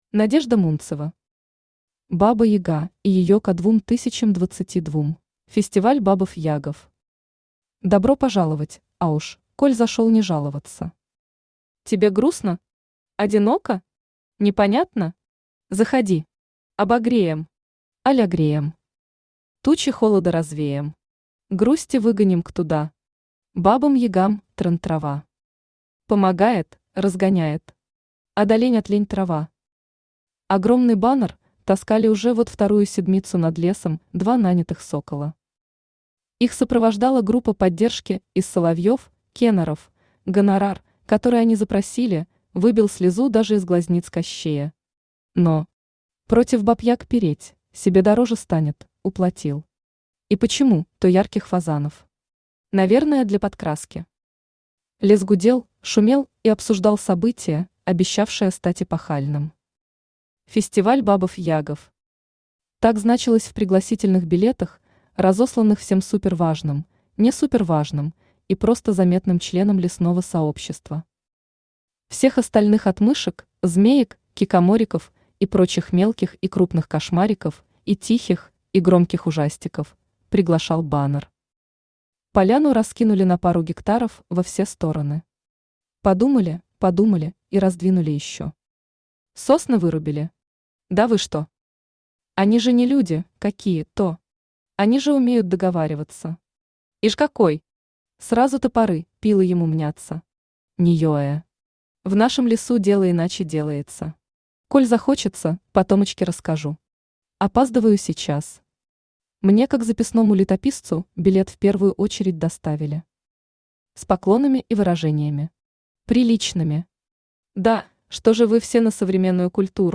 Aудиокнига Баба Яга и её Ко 2022 Автор Надежда Михайловна Мунцева Читает аудиокнигу Авточтец ЛитРес.